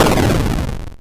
explosion2.wav